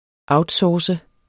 Udtale [ ˈɑwdˌsɒːsə ]